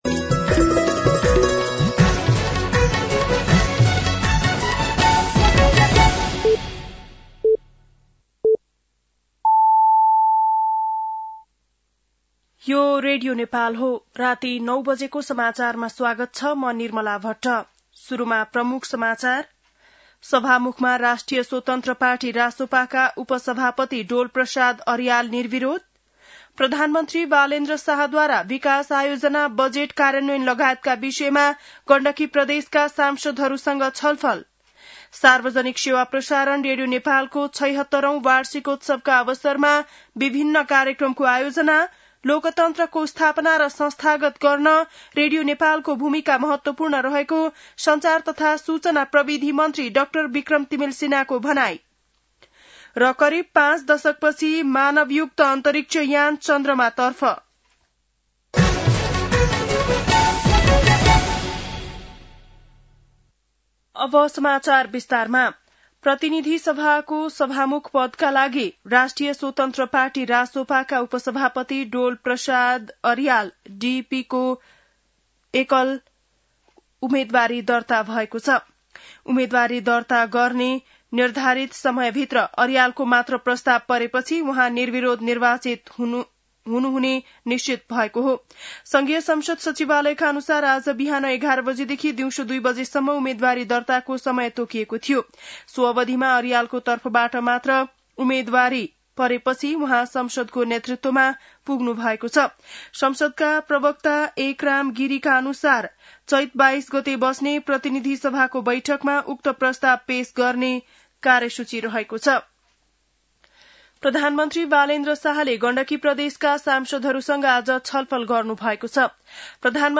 बेलुकी ९ बजेको नेपाली समाचार : २० चैत , २०८२
9-PM-Nepali-NEWS-12-20.mp3